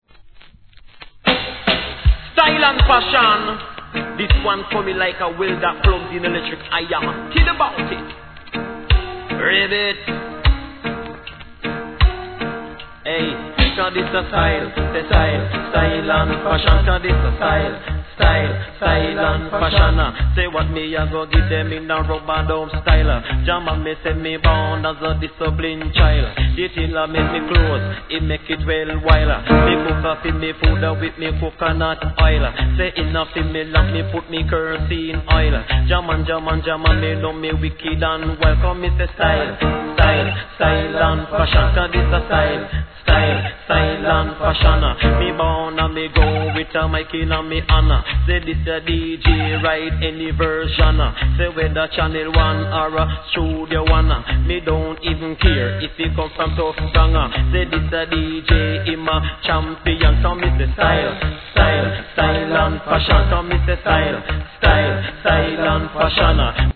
REGGAE
後半DUB接続!